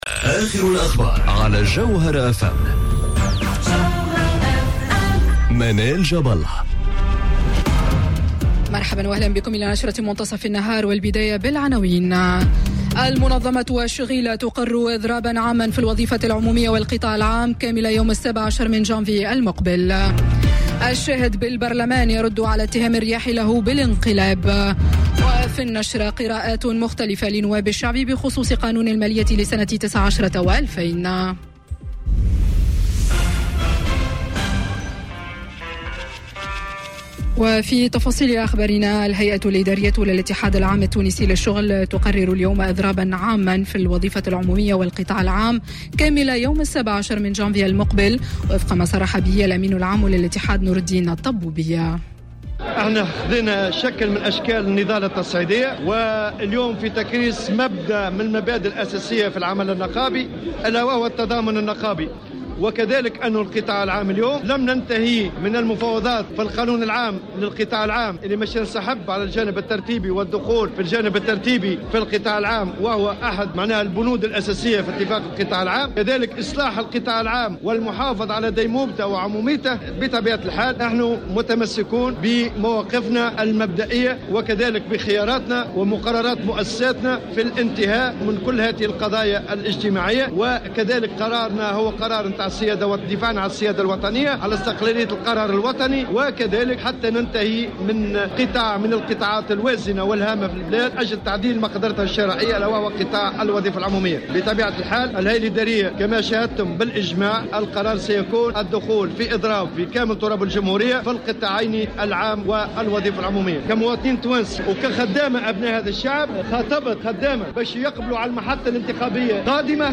نشرة أخبار منتصف النهار ليوم السبت 24 نوفمبر 2018